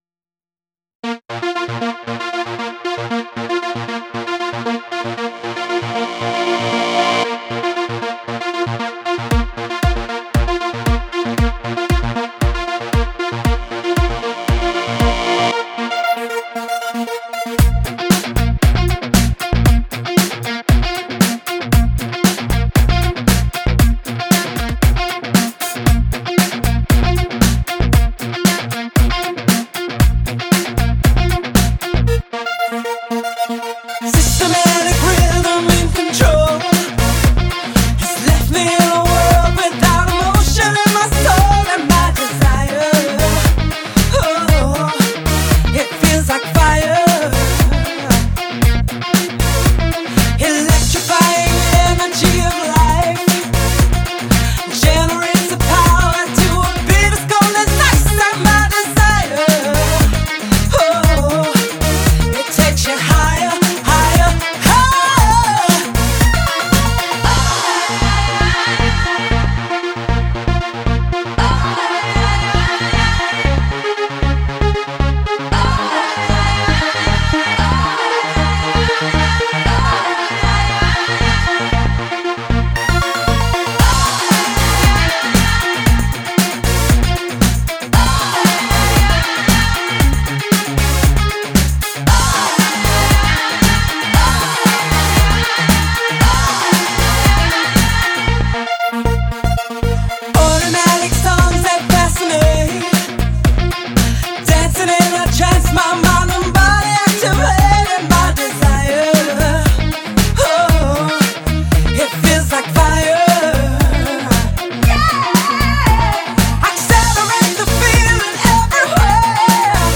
акапелла найдена на просторах сети ---но в жёстком mp3